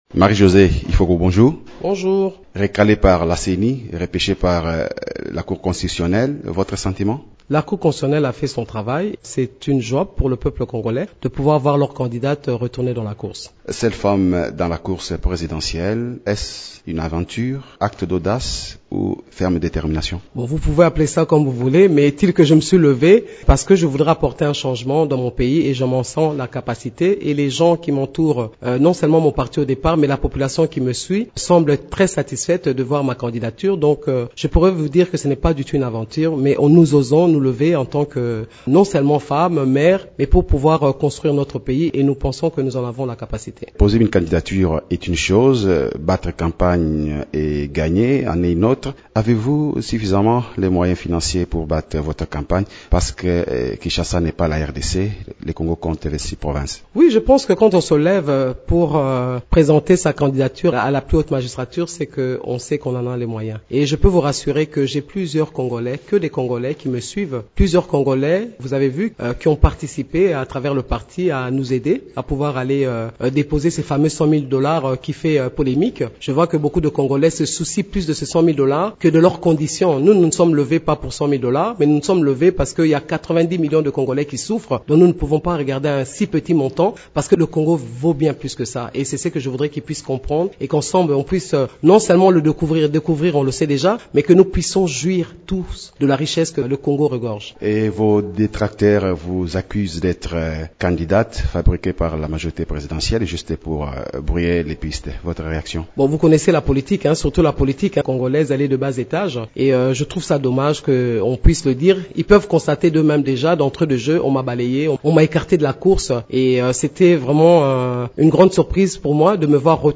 Elle affirme par ailleurs n’avoir pas peur d’être l’unique femme au milieu des candidats hommes.